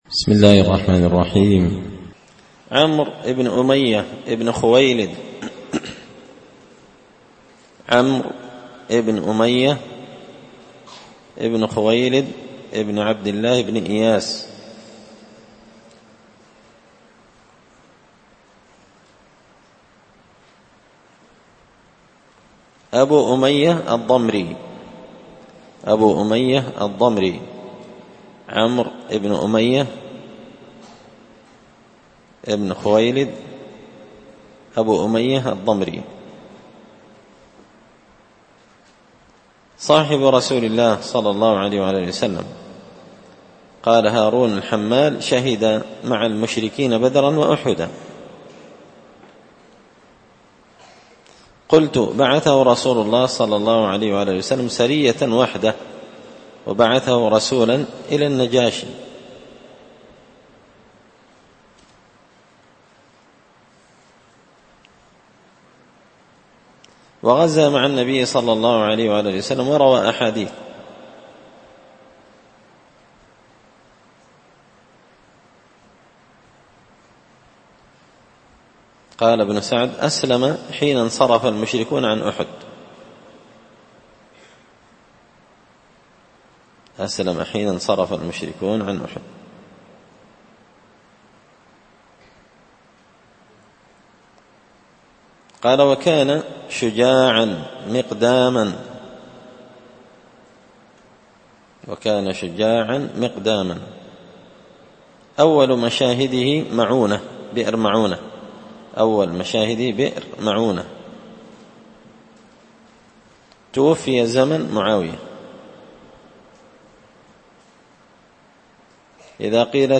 الدرس 186عمرو بن أمية – قراءة تراجم من تهذيب سير أعلام النبلاء
دار الحديث بمسجد الفرقان ـ قشن ـ المهرة ـ اليمن